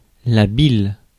Ääntäminen
Synonyymit fiel Ääntäminen France Tuntematon aksentti: IPA: /bil/ Haettu sana löytyi näillä lähdekielillä: ranska Käännös Konteksti Substantiivit 1. bile biokemia 2.